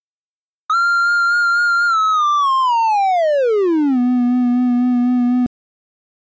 • O son baixa; comeza agudo e convértese en grave.
son_baixando.mp3